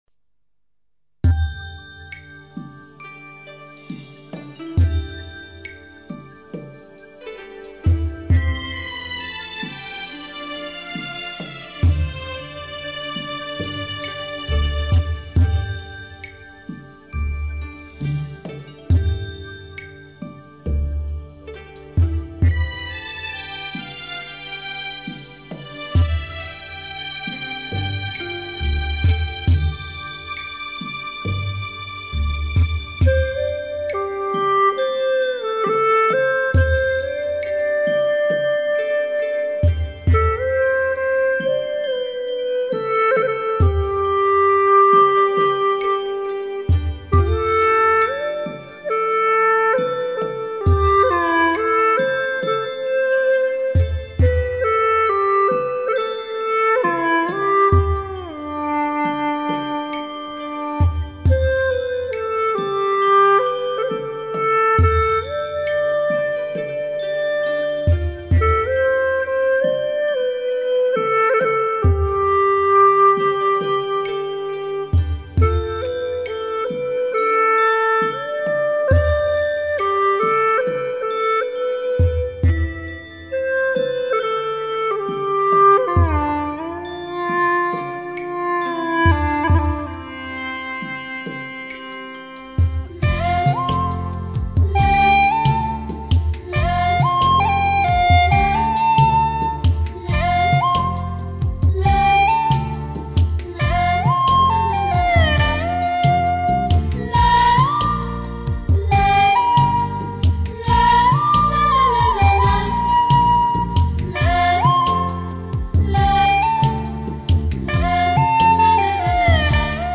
调式 : F 曲类 : 独奏
典雅庄重，深受人民喜爱，除用葫芦丝独奏外还多被用于演唱和舞蹈。